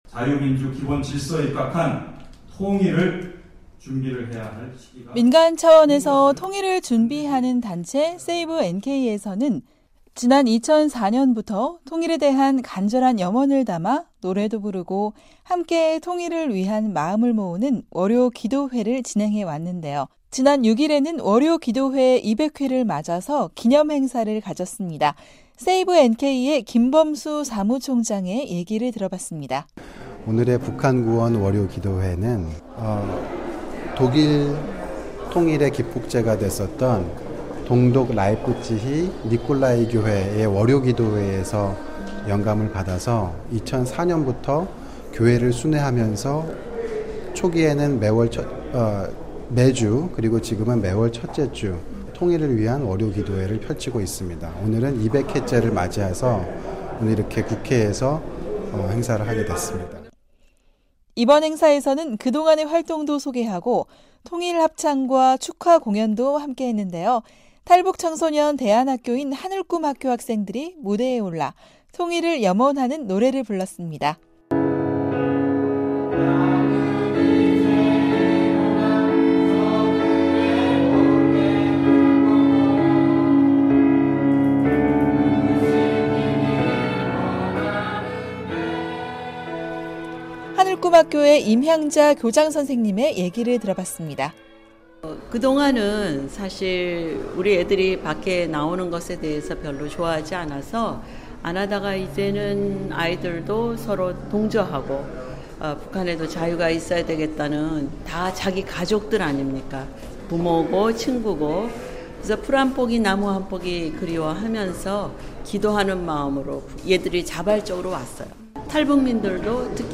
6일 한국 국회의원회관에서 북한 구원 기도회 200회 기념행사가 열렸다.